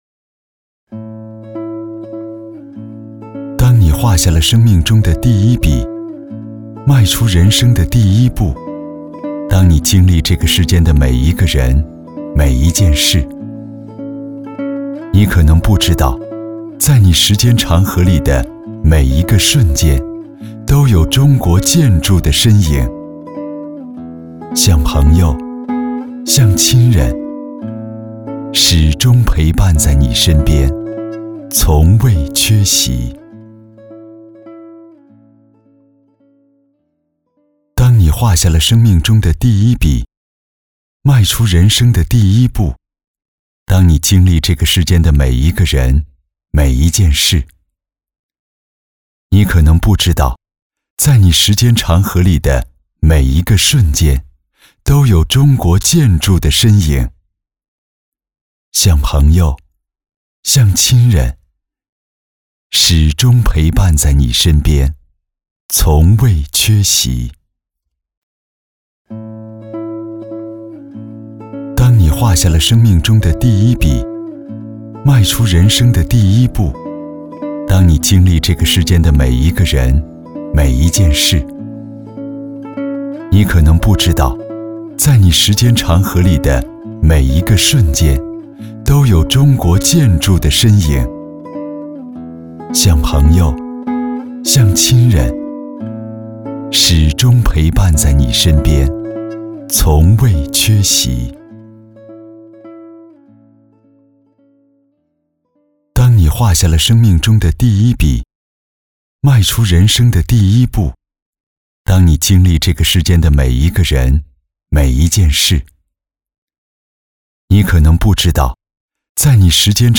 • 男1 国语 男声 【温馨旁白】中国建筑形象片 大气浑厚磁性|沉稳|娓娓道来|素人